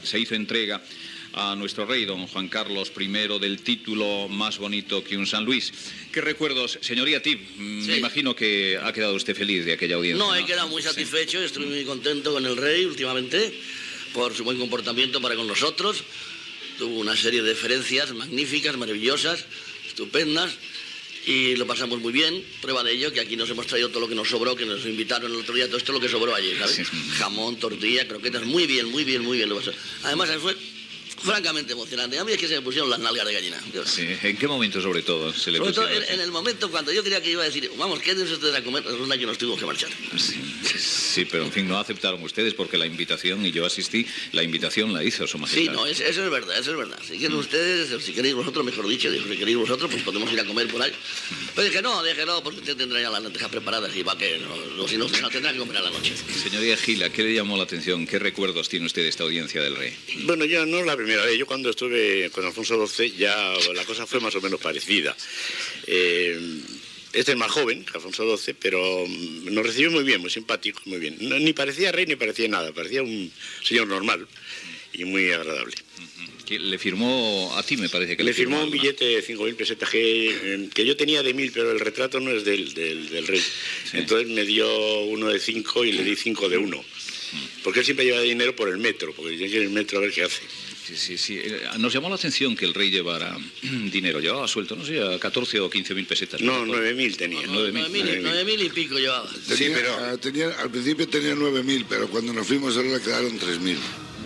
Comentaris amb els humoristes Tip, Coll, Miguel Gila i Forges després del lliurament del títol "Más bonito que un San Luis" al Rei Joan Carles I
Info-entreteniment